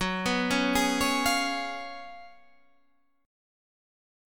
GbM7sus2sus4 chord